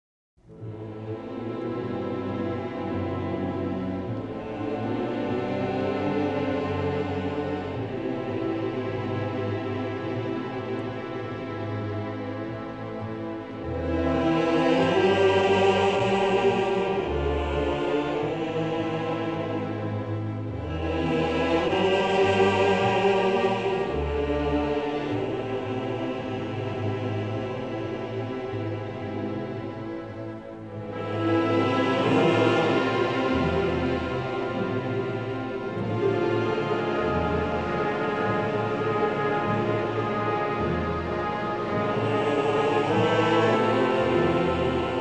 eerie, dirge-like music for a continent gone mad
newly remastered from the best possible stereo sources